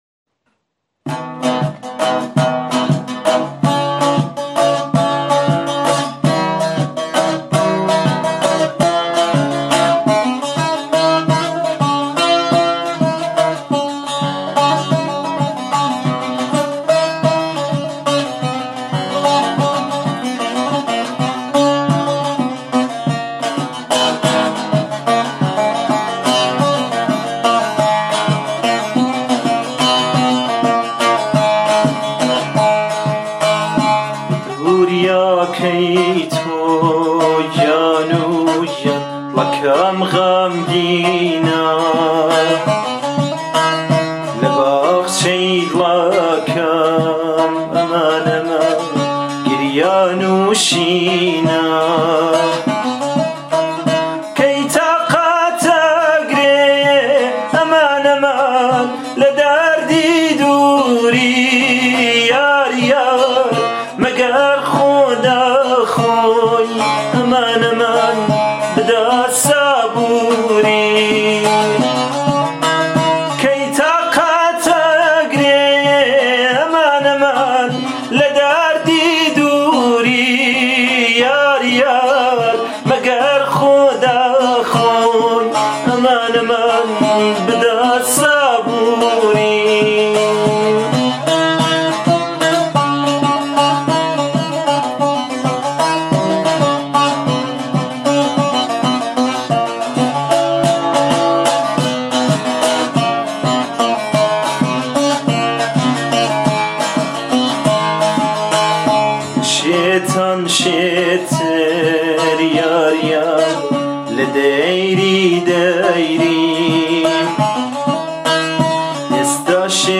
ترانه کردی
اجرا شده با گیتار – فولکلور